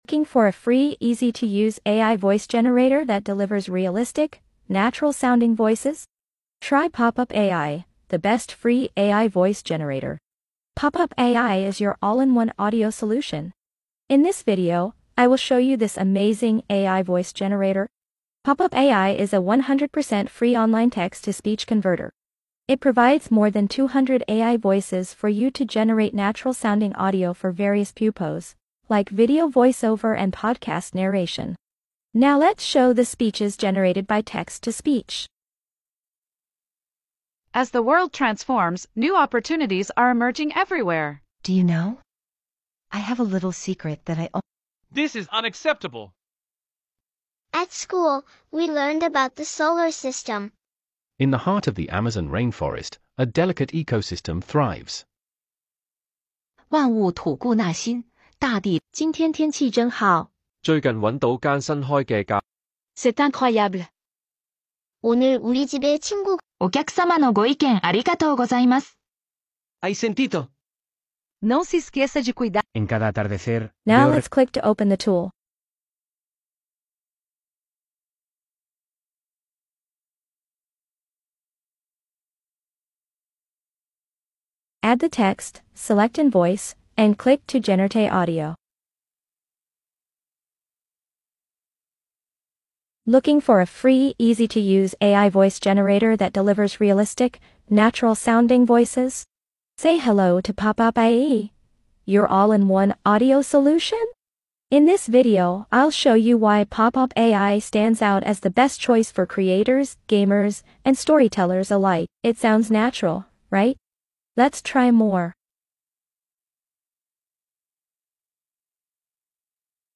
the best Mp3 Sound Effect PopPop AI - the best free AI voice generator that turns text into natural-sounding speeches online.